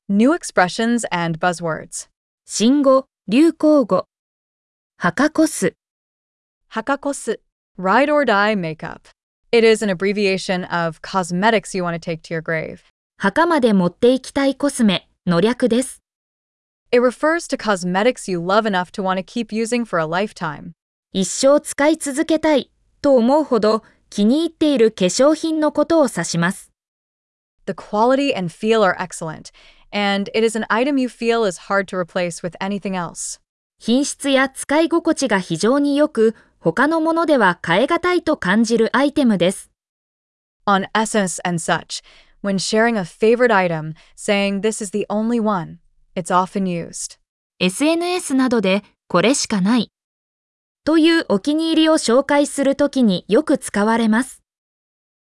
🗣 pronounced: Hakakosu